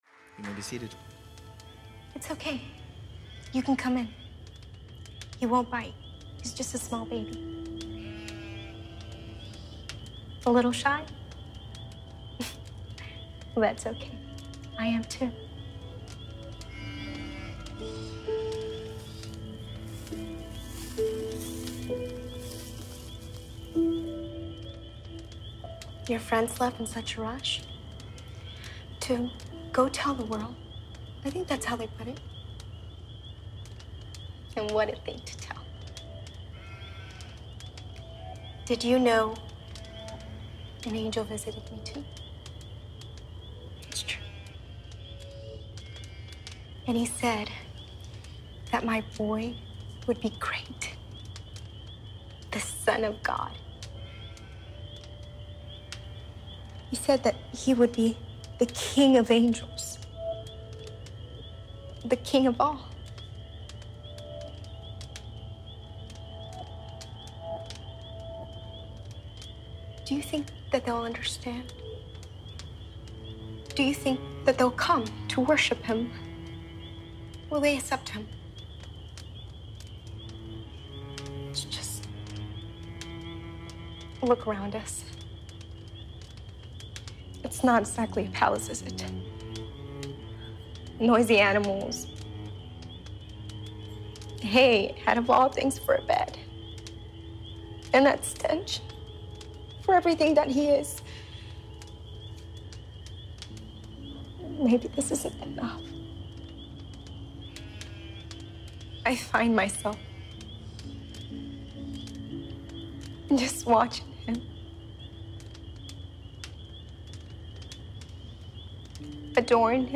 Sermons | CrossWinds Church